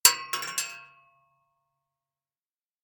Bullet Shell Sounds
rifle_metal_3.ogg